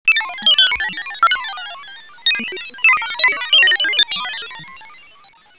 scifi4.wav